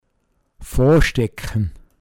Wortlisten - Pinzgauer Mundart Lexikon